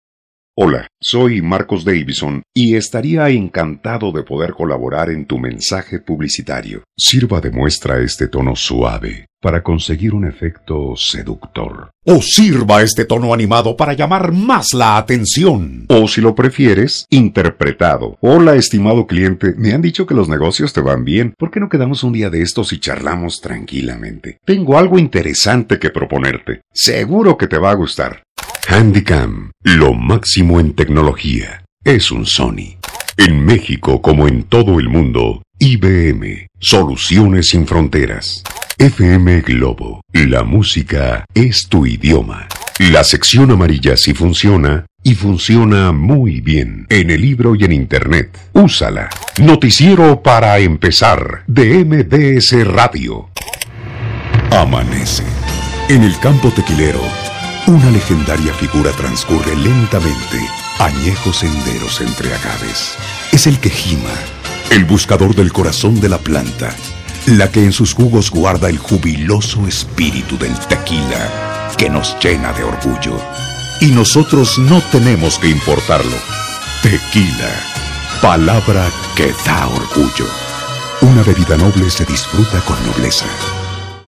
Sprecher mexikanisches spanisch LOCUTOR MEXICANO DE AMPLIA EXPERIENCIA
Sprechprobe: Industrie (Muttersprache):